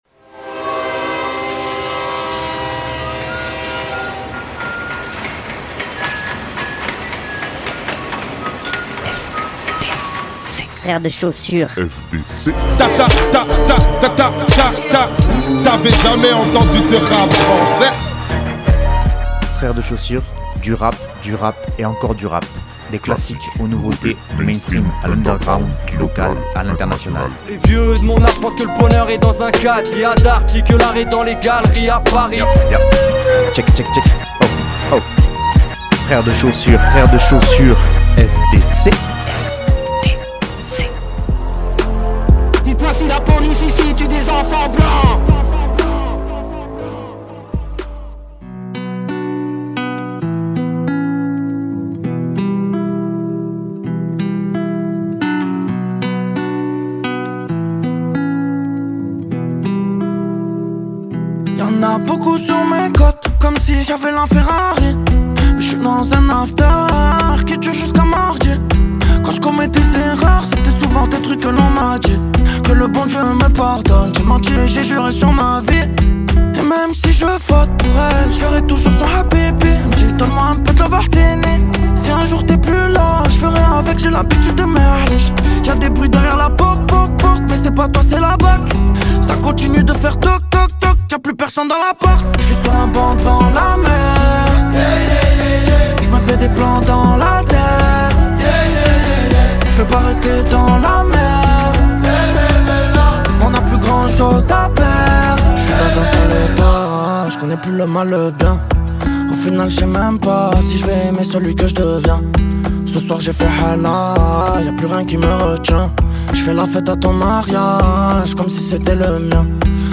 Mais ici c’est que le rap.